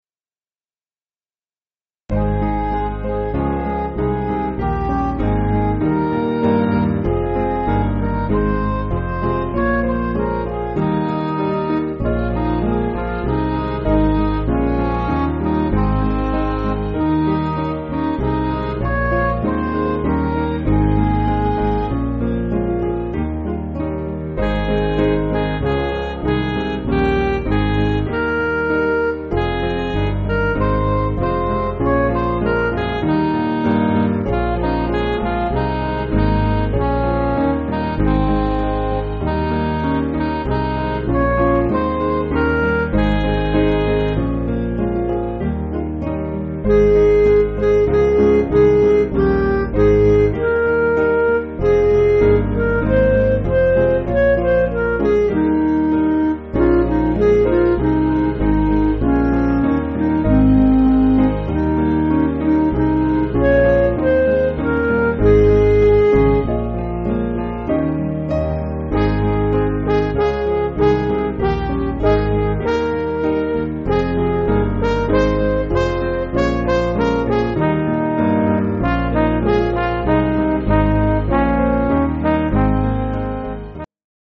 Piano & Instrumental
(CM)   4/Ab